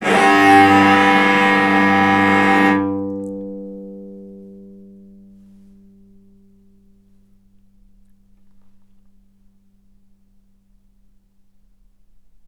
vc_sp-G2-ff.AIF